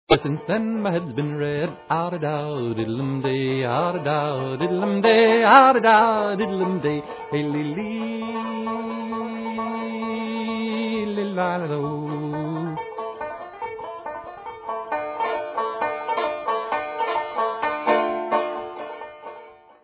(little & big), remastered, two on one
sledovat novinky v oddělení Folk